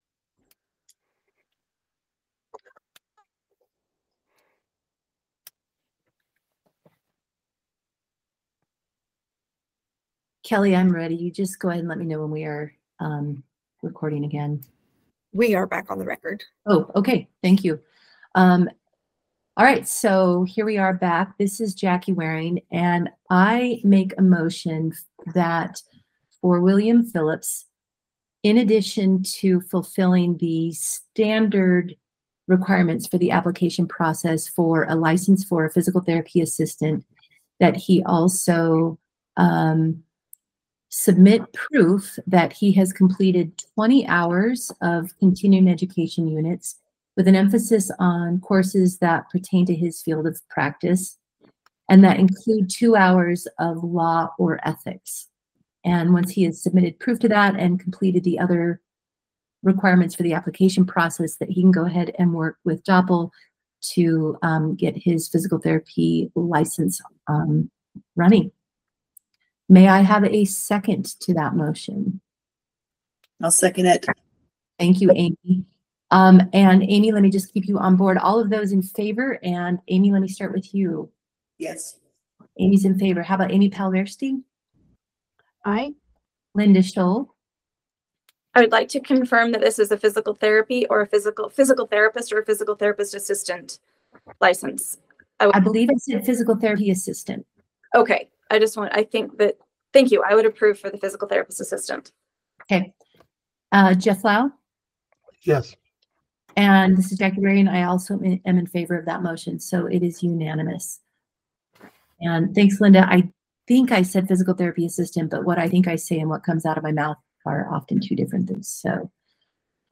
Meeting
Electronic participation is planned for this meeting.